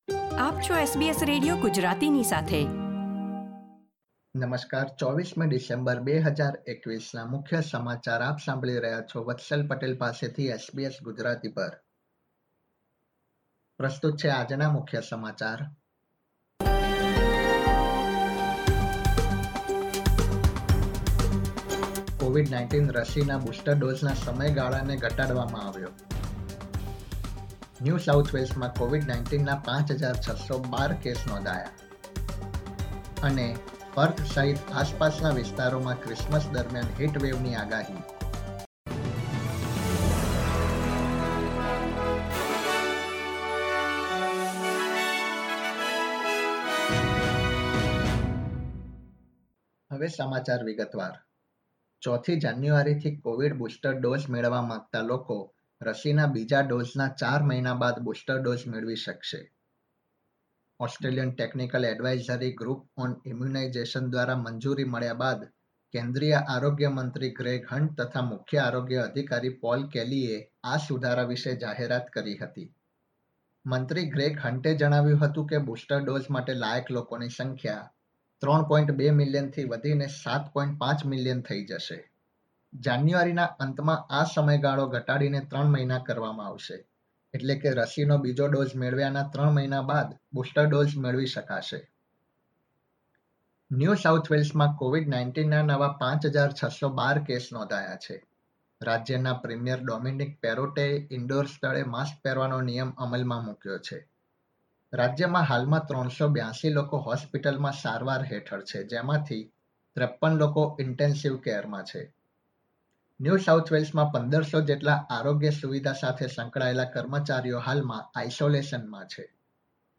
SBS Gujarati News Bulletin 24 December 2021